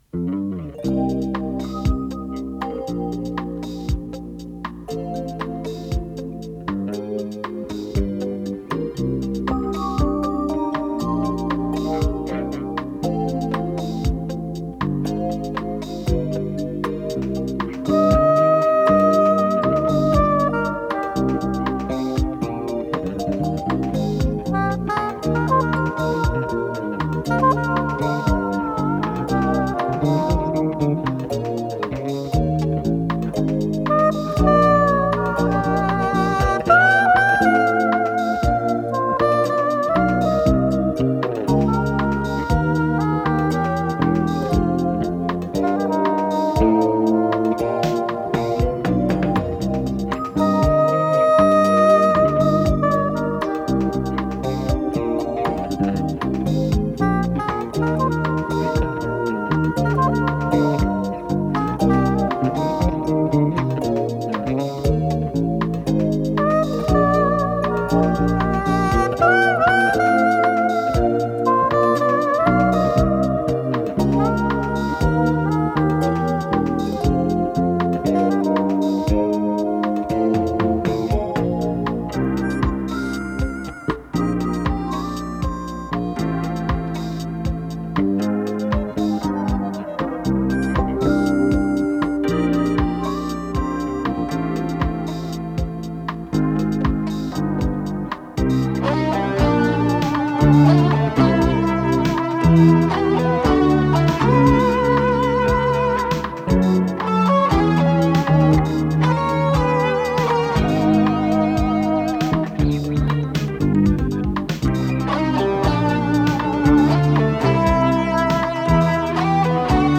саксофон
электрогитара